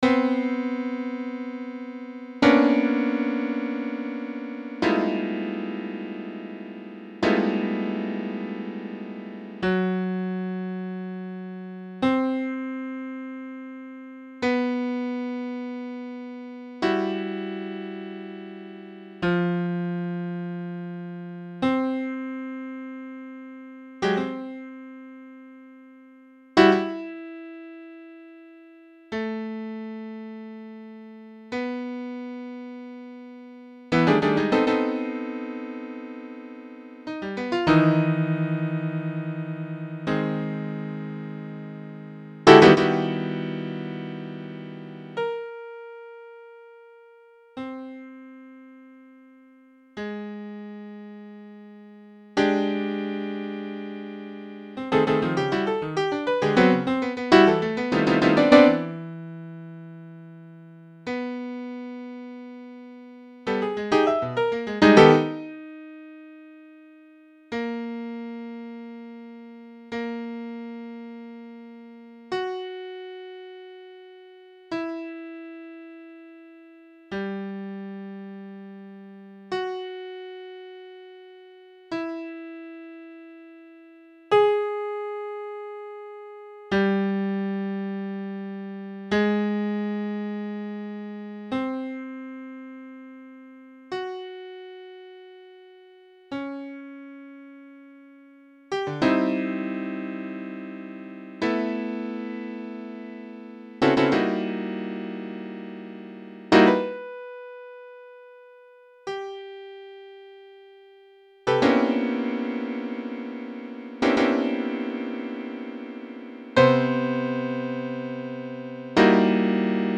piano [10']
MIDI performance